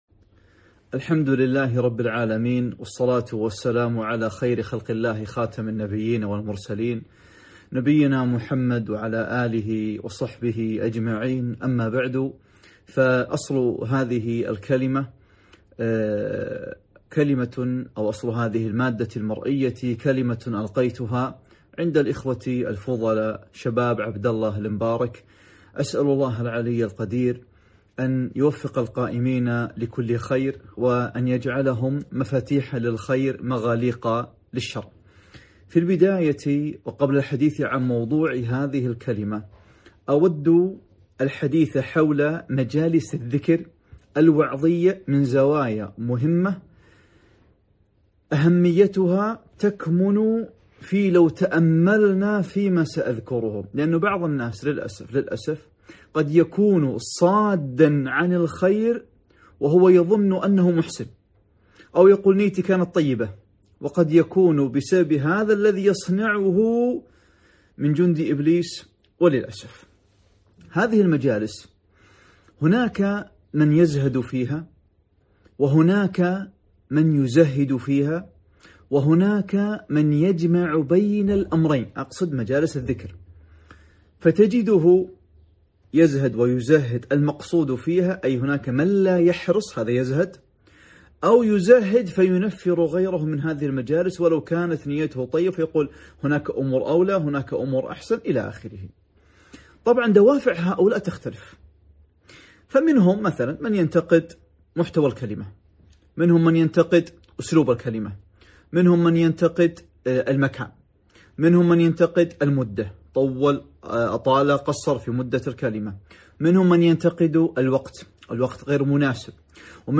محاضرة - هذه هي الحياة